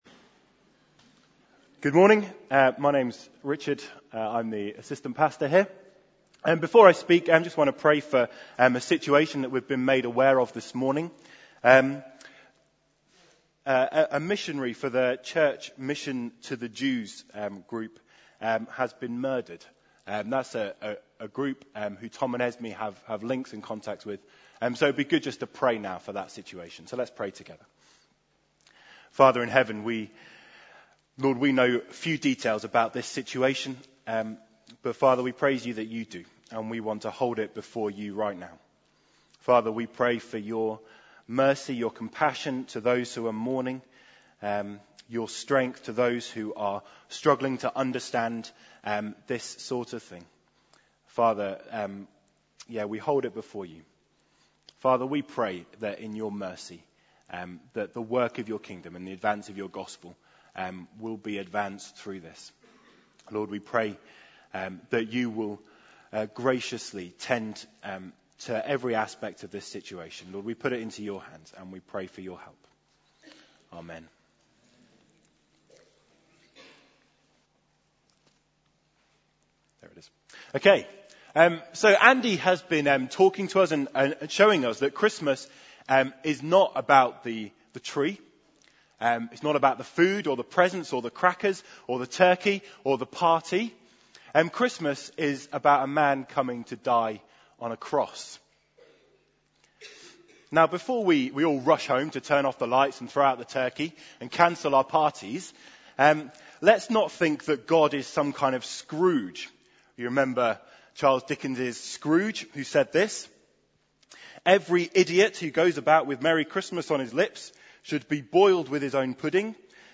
Carol Service – Advent